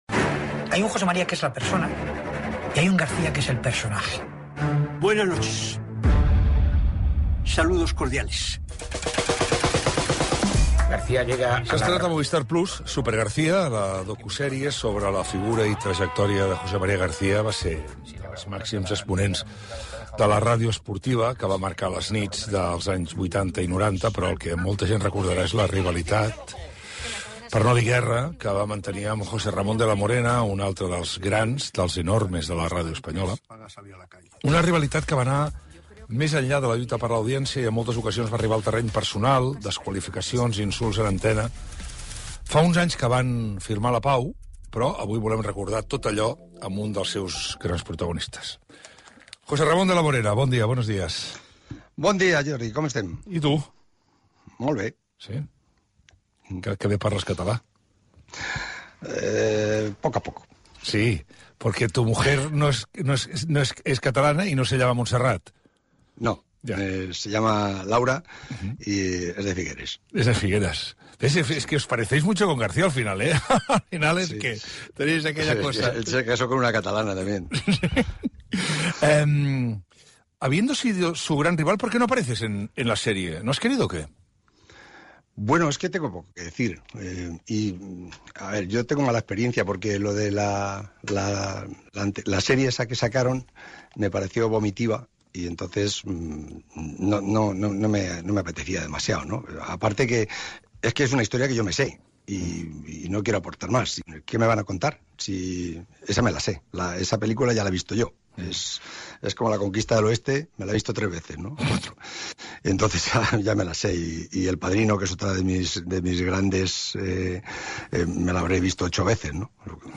1a43b415871ca7b3ed0c8d46b576e9992eda92e8.mp3 Títol RAC 1 Emissora RAC 1 Barcelona Cadena RAC Titularitat Privada nacional Nom programa El món a RAC 1 Descripció Entrevista a José Ramón de la Morena, una vegada estrenada la sèrie "Supergarcía", dedicada a José María García, sobre les seves disputes d'anys ençà. Gènere radiofònic Info-entreteniment Presentador/a Basté